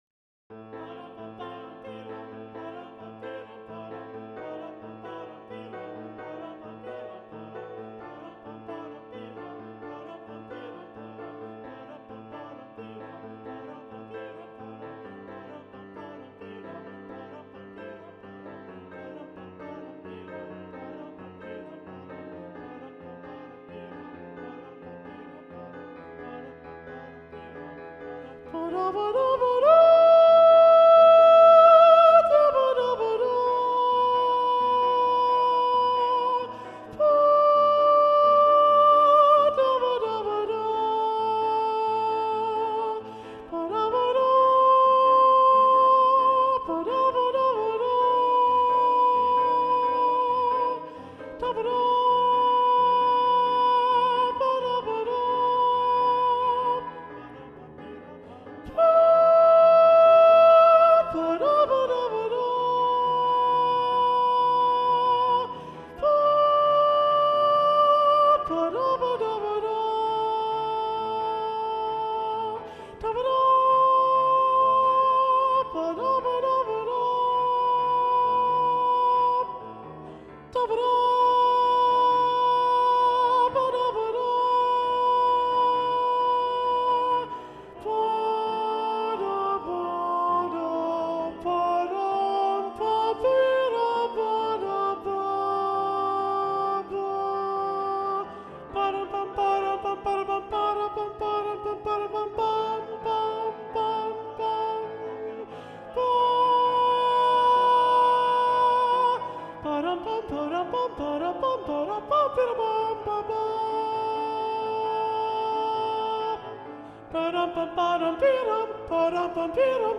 - Œuvre pour chœur à 7 voix mixtes (SSAATTB) + piano
SATB Soprano 2 (chanté)